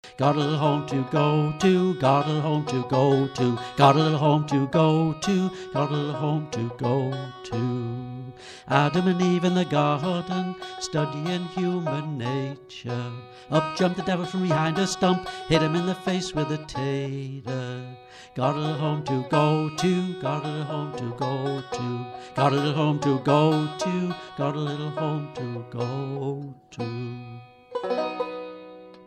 Years later (don't ask!) - and still loving that crisp, plinckety sound - playing the banjo and singing has almost become an obsession.
He is mostly into Old Time American folk music, and has collected a sizeable portfolio of Appalachian Mountain music, American Civil War songs, plantation songs and early Bluegrass material.